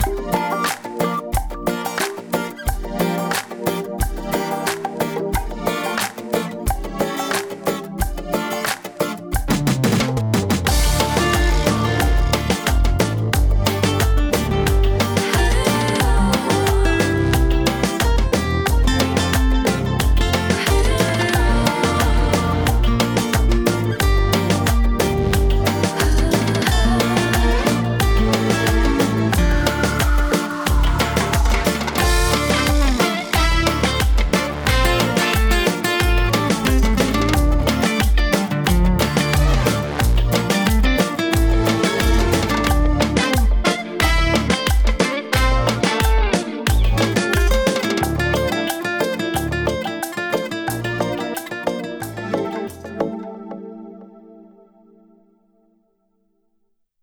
Жестковато вышло, атаки попёрли и окрашено, не для такого рода музыки. Правда учитывая, монофоничность прибора, каждый канал раздельно прогонял. Вложения микс_compression.wav микс_compression.wav 9,6 MB · Просмотры: 135